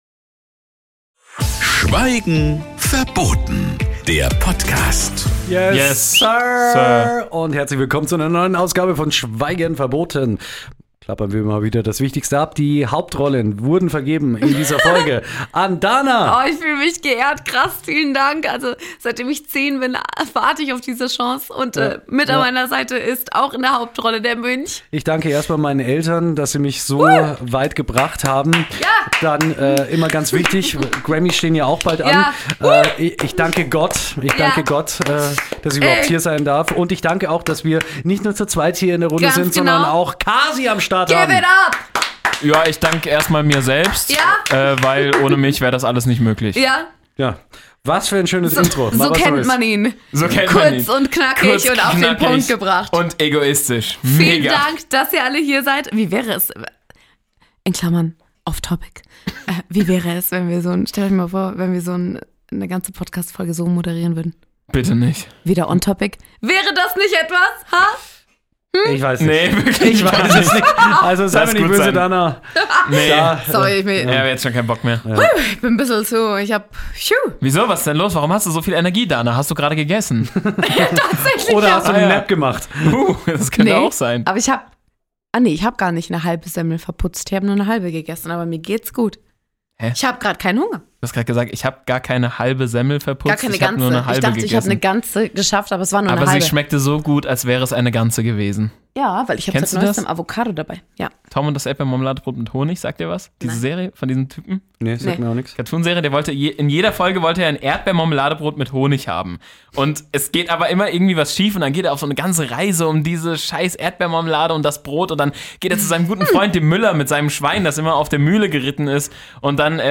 Aber jetzt hat sie die Offenbarung darüber gefunden, was Hobbys überhaupt sind. Und das muss natürlich sofort in Musikform verkündet werden.